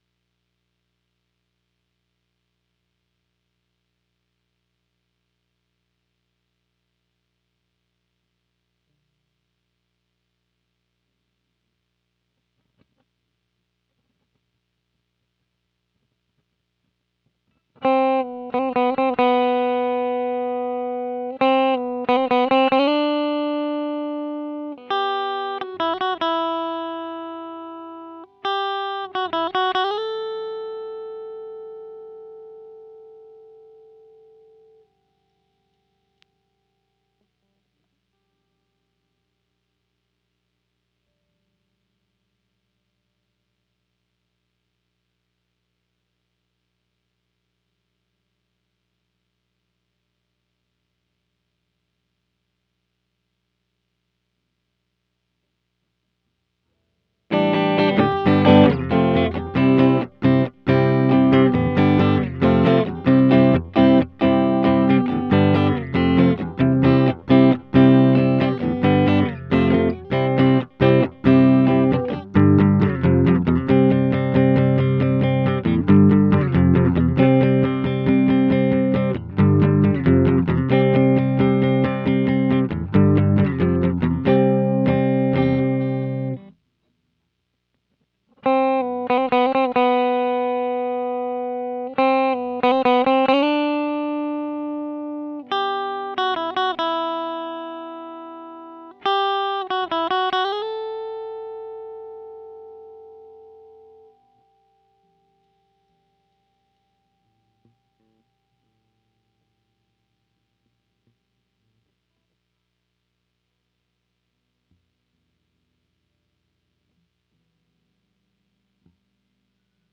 Guitar_010.wav